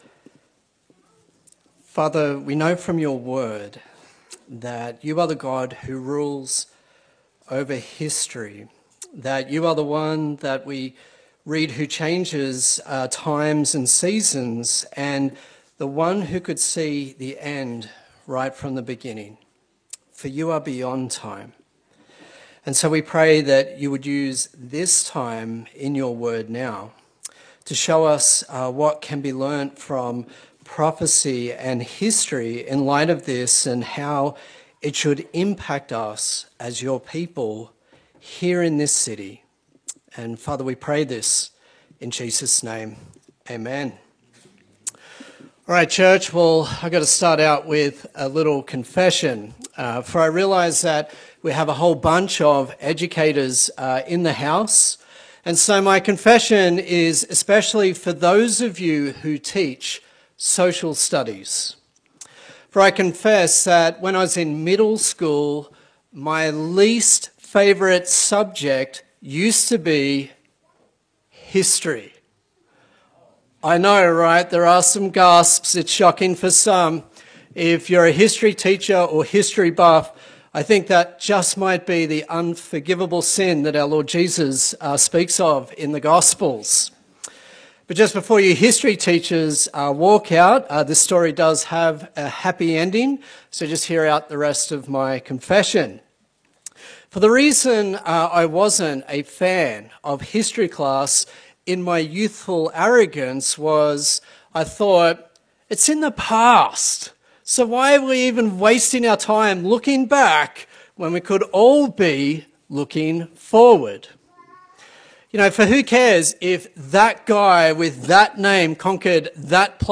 Daniel Passage: Daniel 8 Service Type: Sunday Service